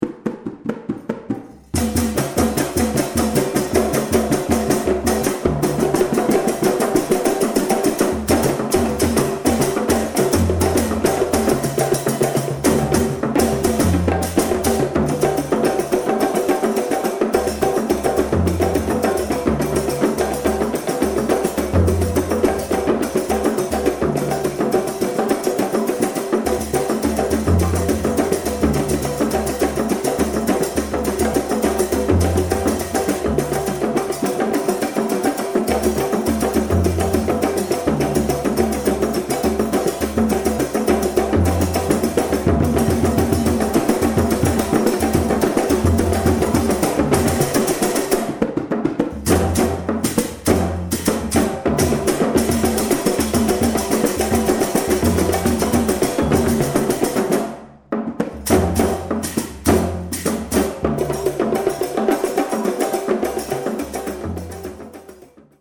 rebana tuned drum group
smiled and said "It is very fast and very intense."